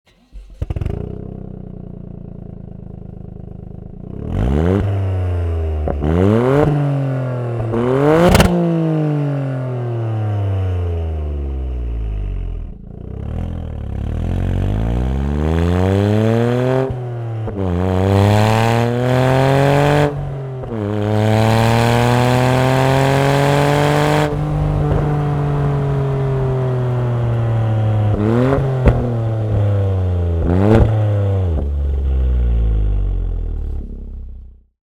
Le son est encore amélioré par un résonateur réglé dans la partie centrale du tuyau de liaison, calculé pour offrir une expérience sonore parfaite, offrant un son profond, riche et sportif sans bourdonnement ni streaming, pour créer un son de course pur à trois cylindres du moteur.
Son avec tuyau de liaison Akrapovic :
EVOLUTION-LINK-PIPE-TOYOTA-GR-YARIS.mp3